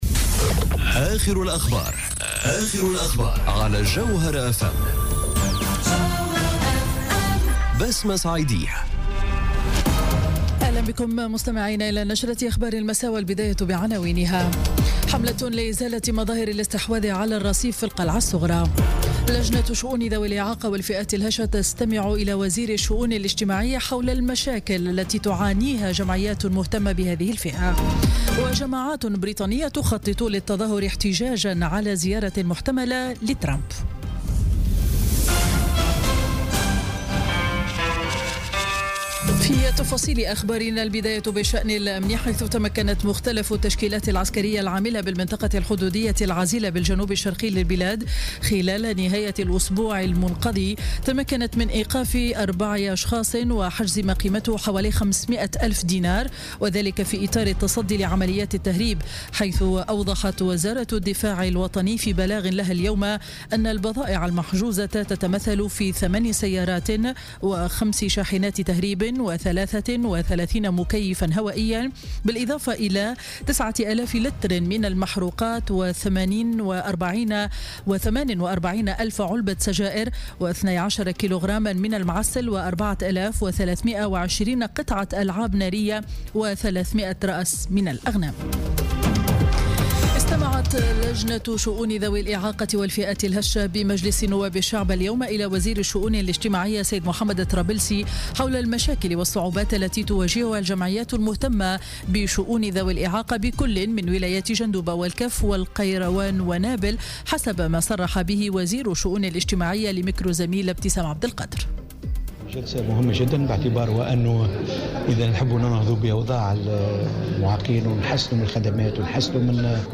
نشرة أخبار السابعة مساء ليوم الاثنين 3 جويلية 2017